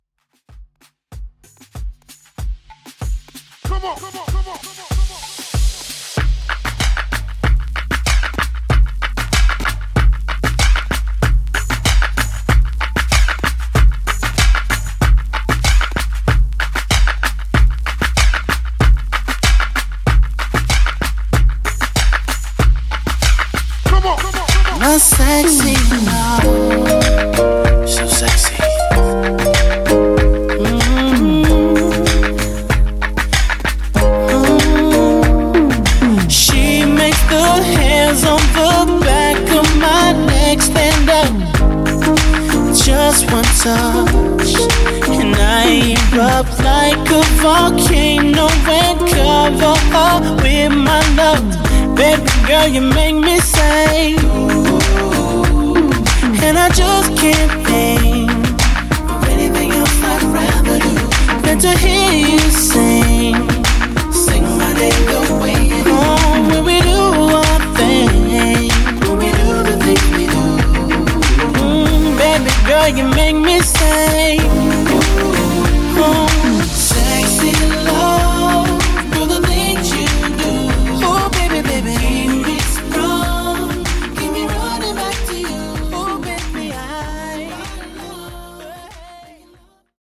R&B Redrum)Date Added